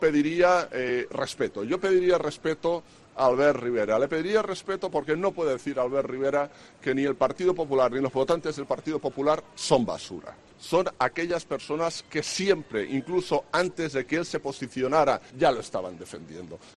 En un acto en L'Hospitalet, el candidato del Partido Popular ha apelado al orgullo de quienes se sienten catalanes y españoles, y ha pedido el voto para "poder recuperar la democracia y la normalidad".